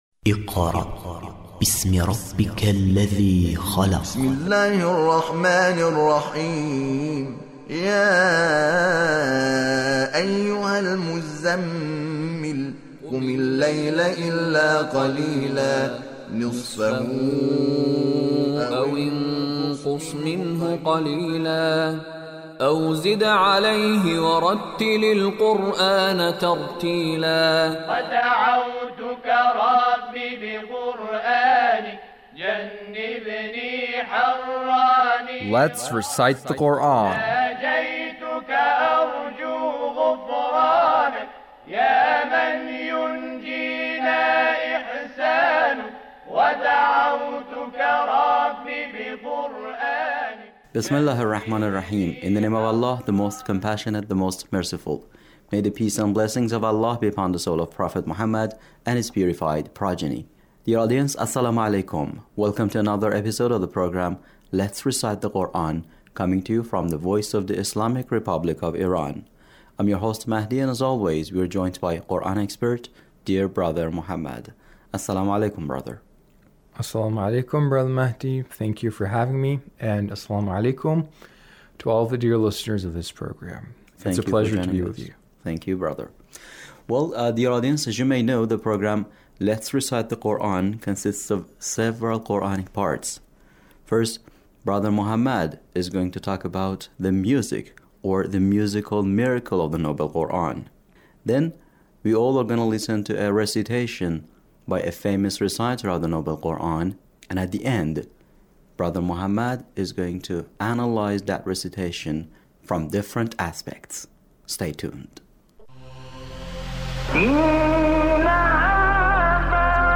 Recitation of sheykh Khalil Alhosari